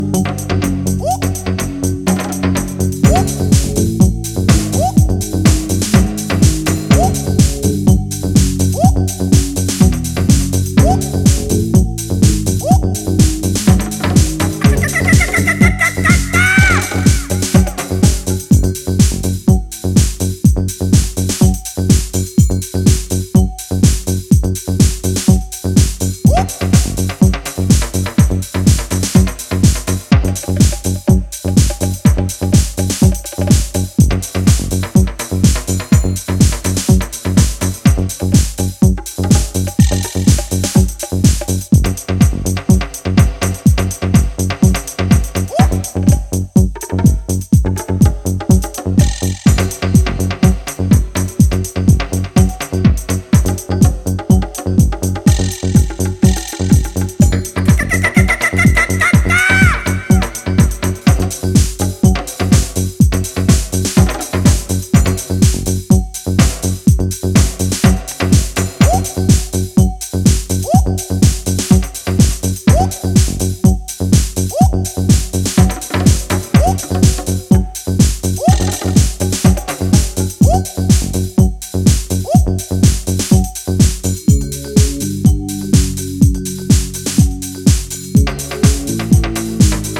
Electronix Ambient Pop